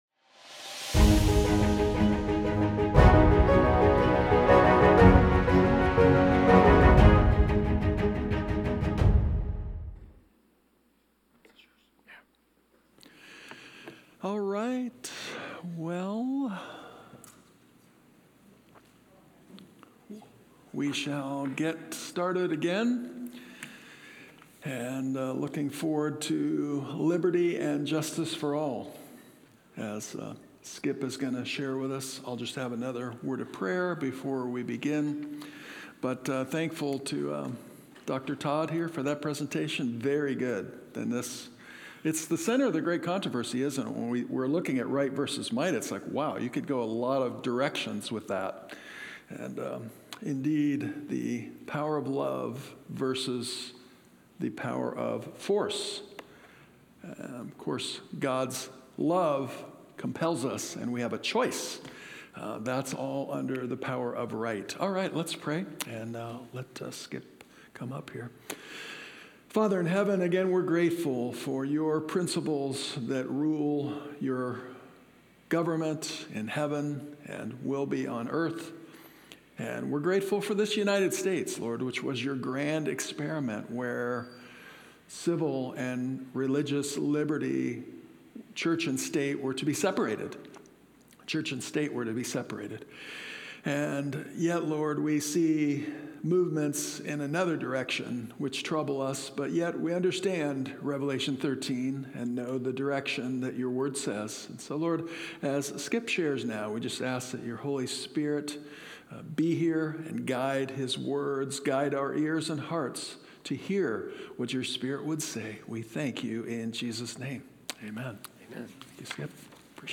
From Series: "Central Sermons"